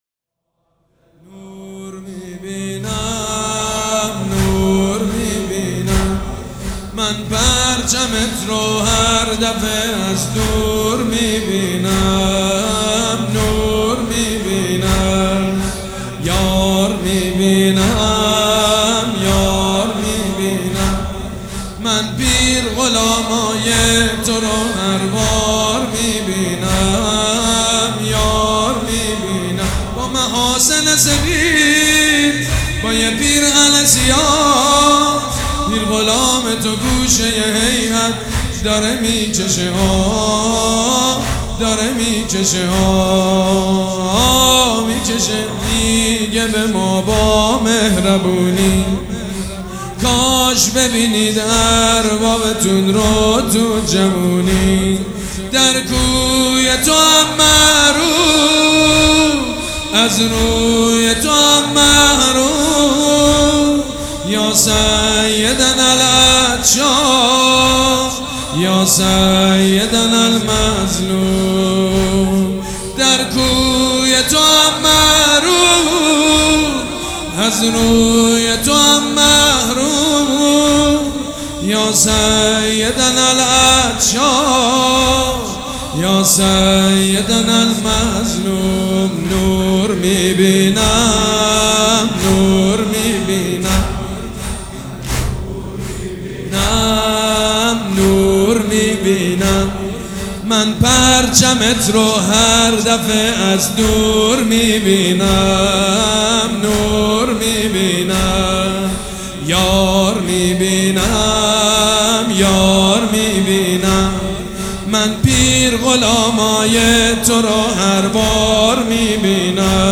مداح
حاج سید مجید بنی فاطمه
مراسم عزاداری شب پنجم